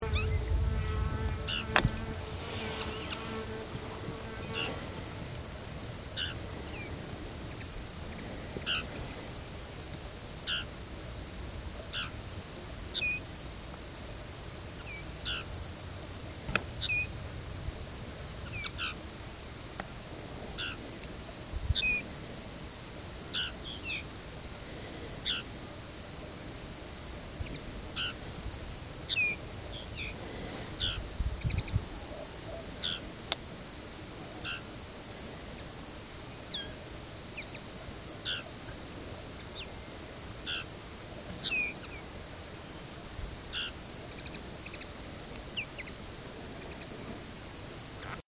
Enfin, un fichier son mp3 d'une conversation des magnifiques Linottes mélodieuses.
Conversation de Linottes mélodieuses
ob_8bae11_linottes-me-lodieuses.mp3